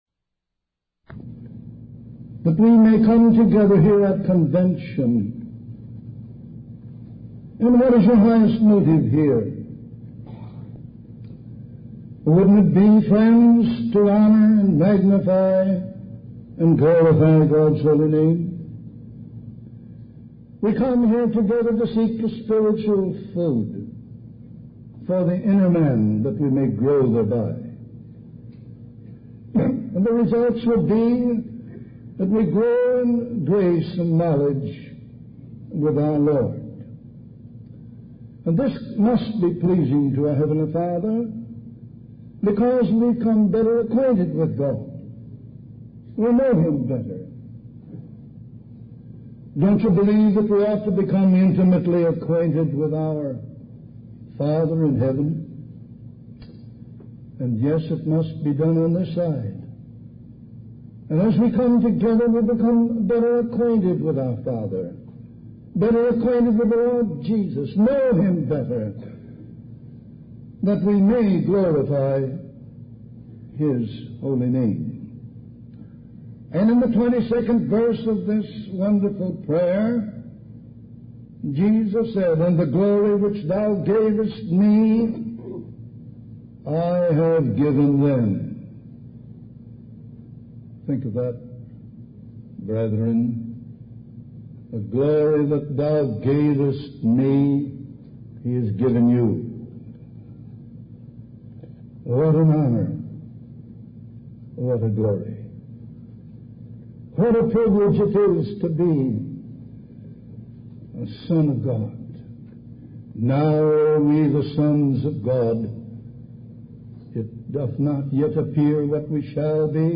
From Type: "Discourse"
some distortion in middle